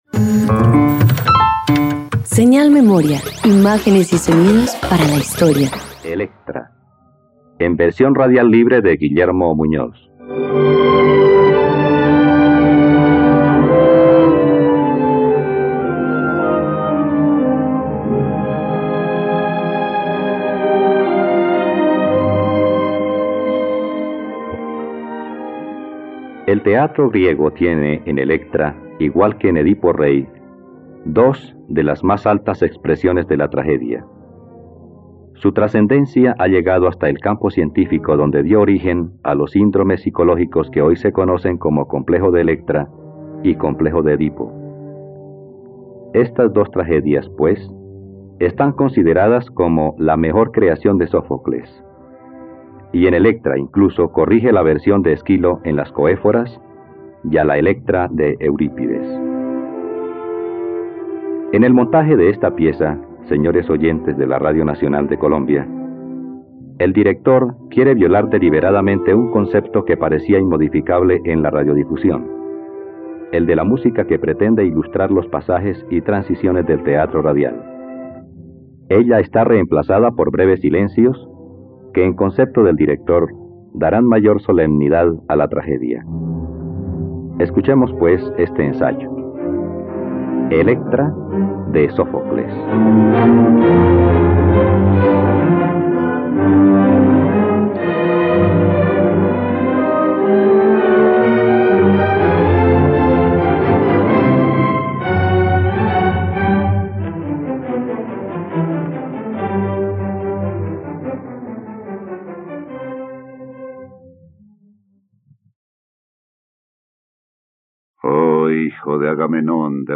.Radioteatro. Escucha la adaptación radiofónica de “Electra” del poeta griego Sófocles por la plataforma streaming RTVCPlay.